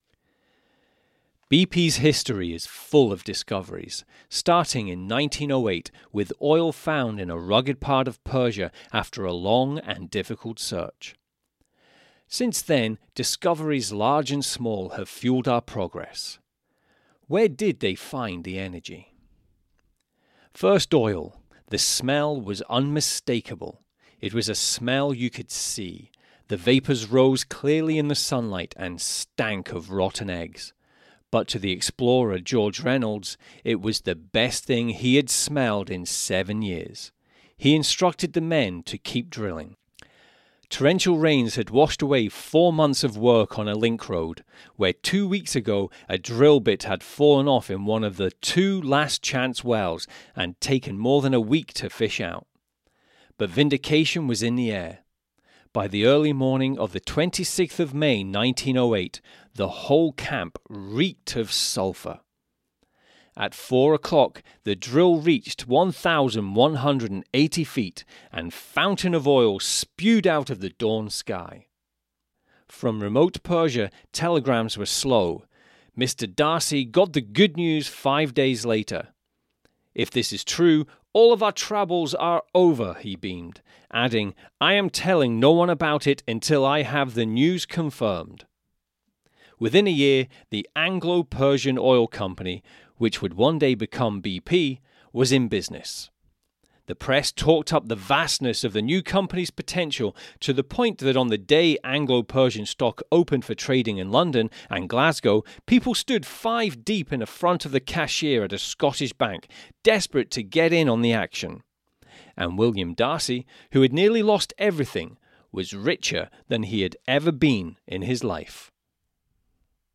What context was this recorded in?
My studio was an alcove inside my large walk in closet with untreated surfaces. It sounded like shit. So, for your listening pleasure, here is an example of one of my first recordings while I was in training from back in 2016.